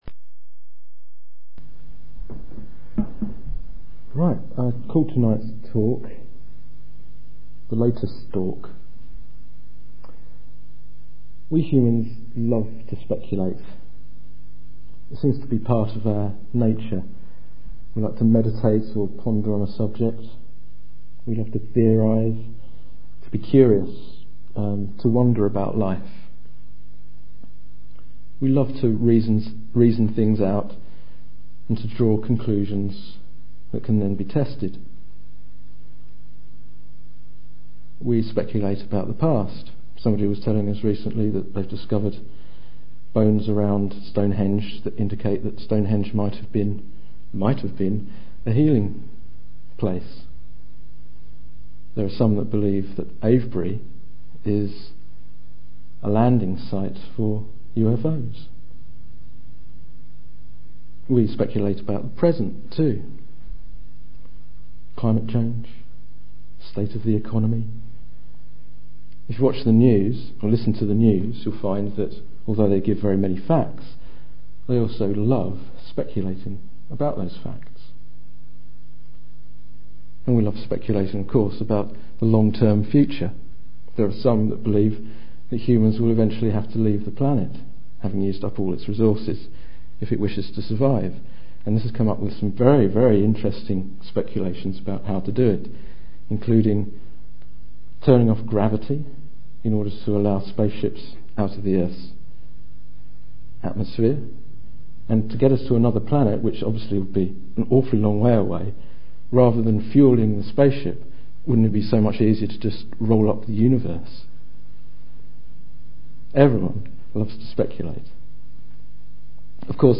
This talk was given in October 2008.